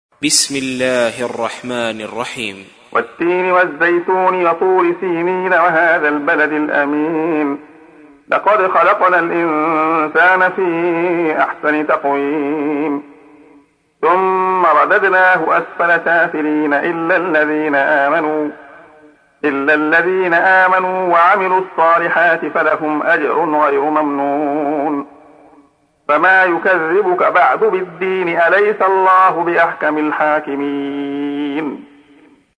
تحميل : 95. سورة التين / القارئ عبد الله خياط / القرآن الكريم / موقع يا حسين